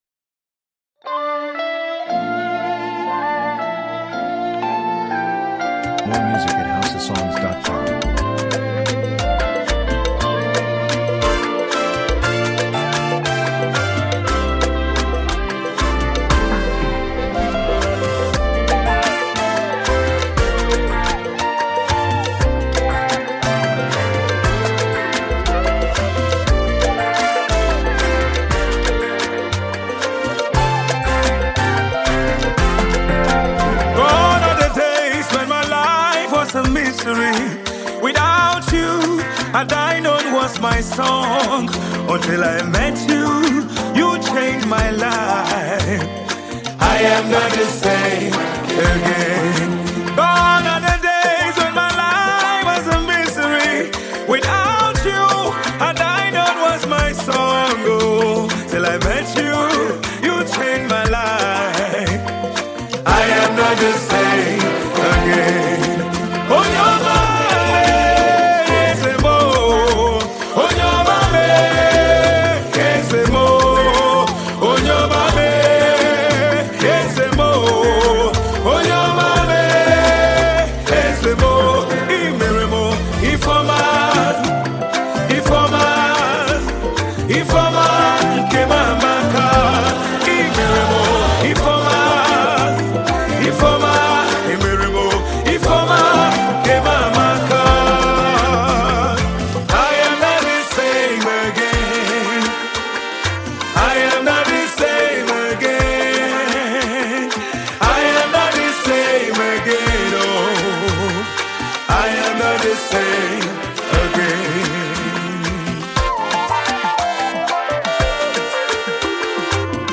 encouraging, uplifts the spirit and soul
Tiv Song